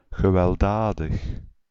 Ääntäminen
IPA: /ɣəʋɛlˈdaːdəx/